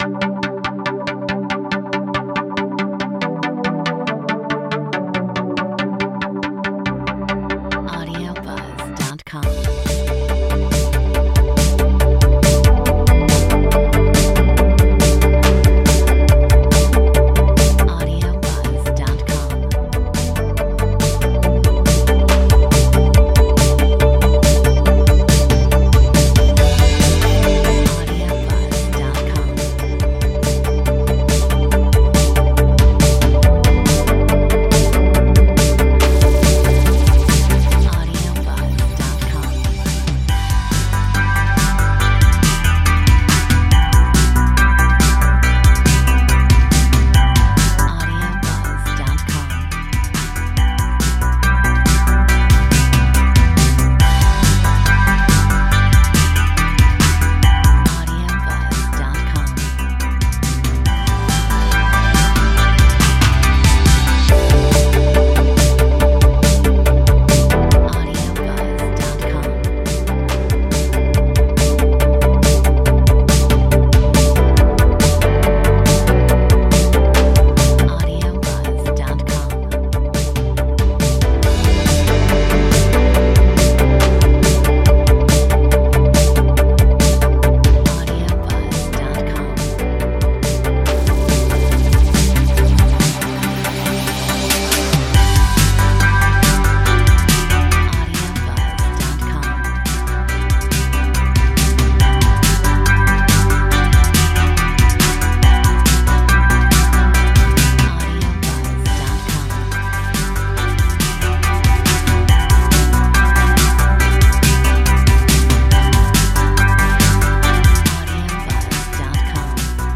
Metronome 140